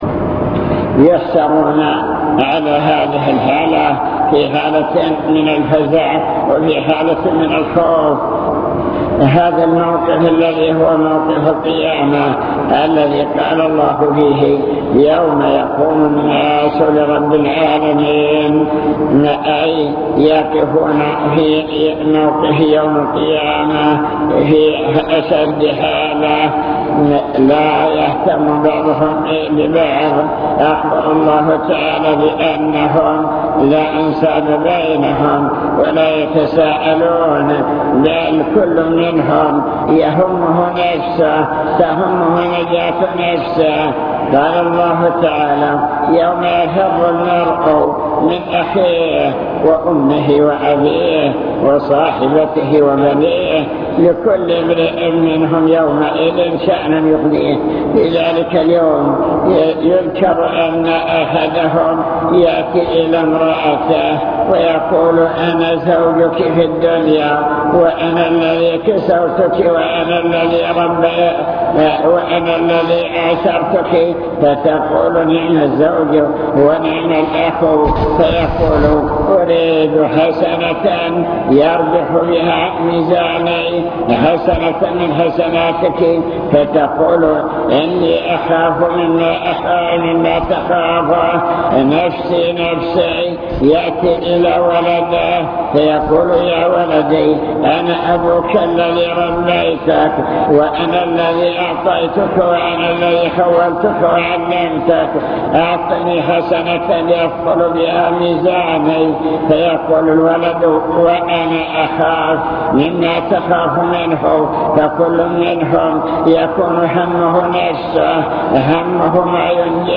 المكتبة الصوتية  تسجيلات - محاضرات ودروس  مواعظ وذكرى